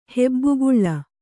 ♪ hebbuguḷḷa